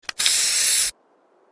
6. Аэрозольная краска